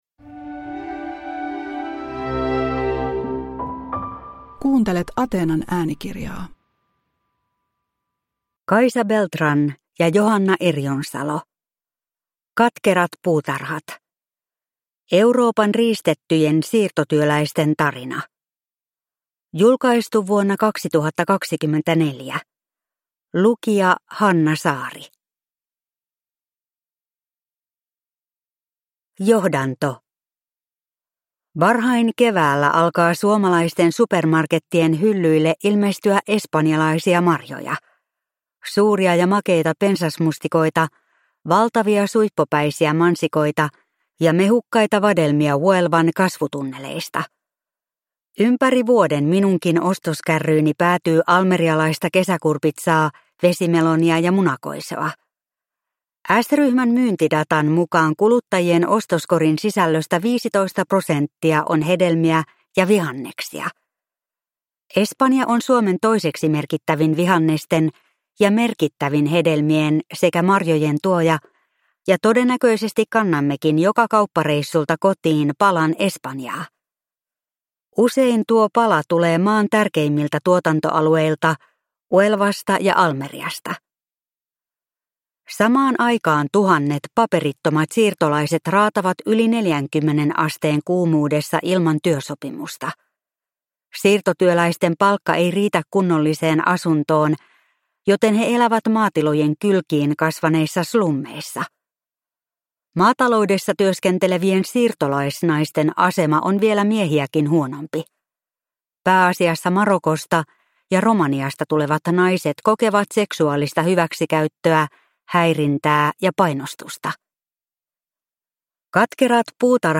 Katkerat puutarhat (ljudbok) av Kaisa Beltran